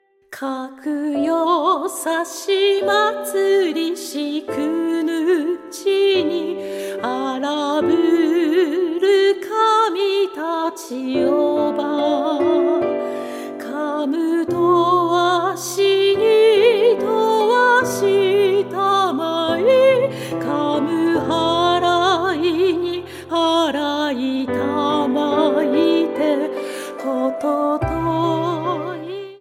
８つの曲を通して、澄んだ歌声と優しい響きが、心に静けさと力を届けます。